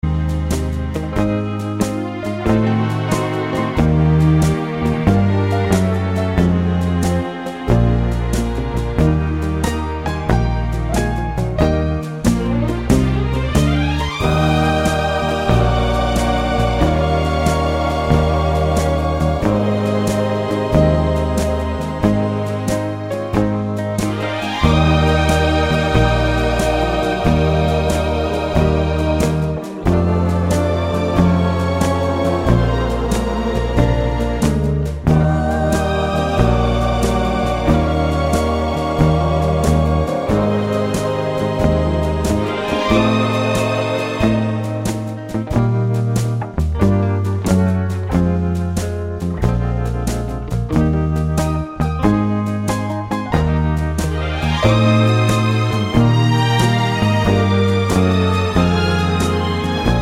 no Backing Vocals Country (Female) 3:45 Buy £1.50